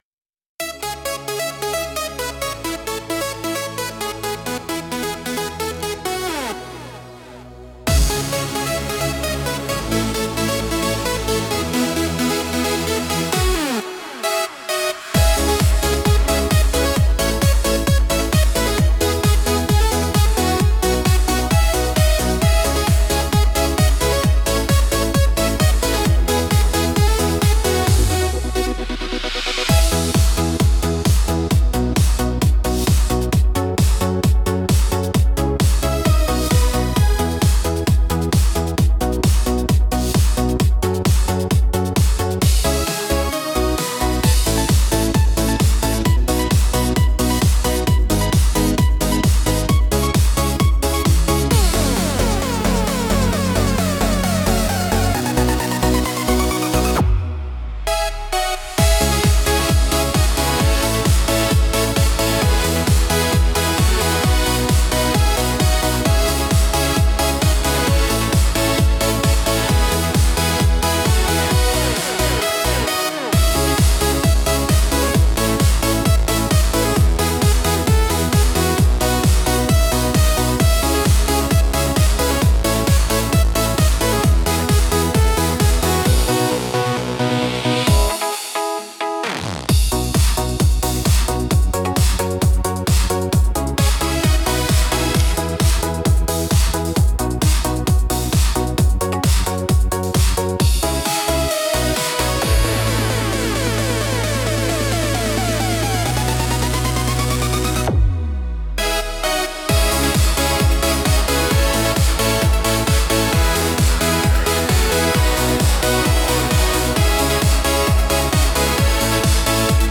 Instrumental - Synthetic Smile 2.37